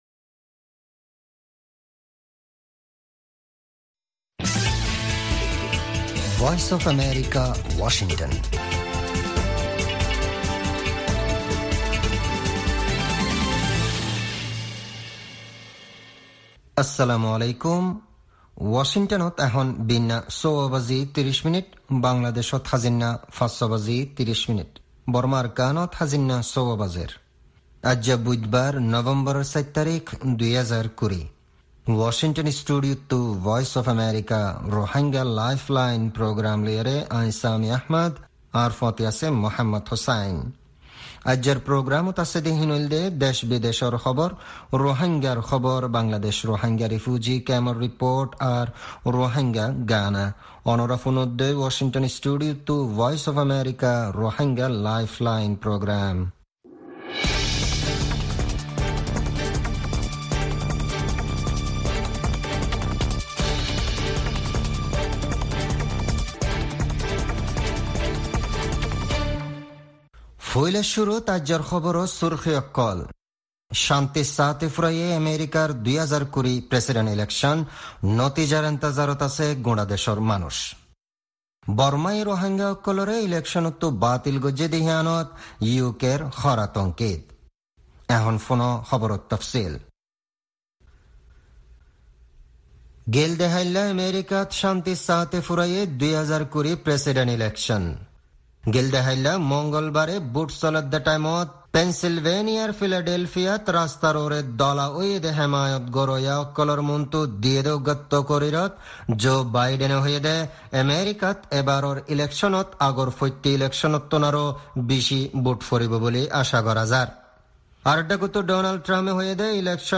Rohingya Broadcast